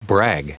Prononciation du mot brag en anglais (fichier audio)
Prononciation du mot : brag